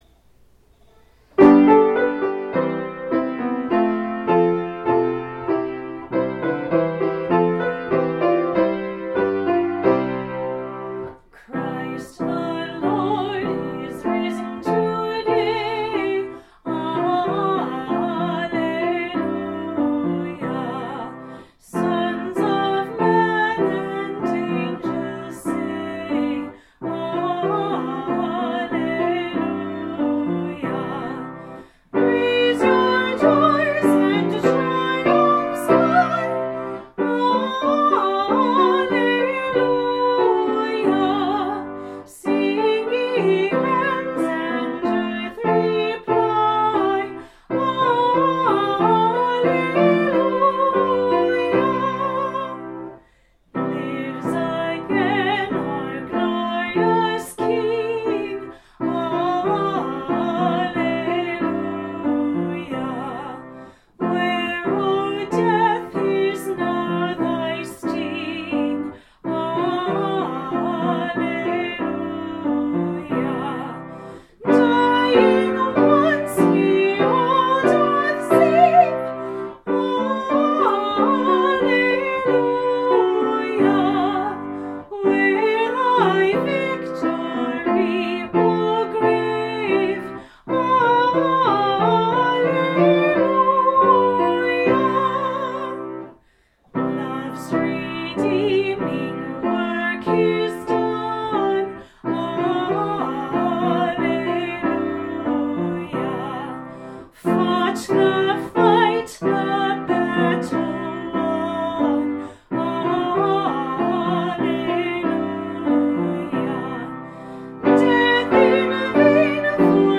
Chapel 4/14/20 – Luke 22-24 Public Reading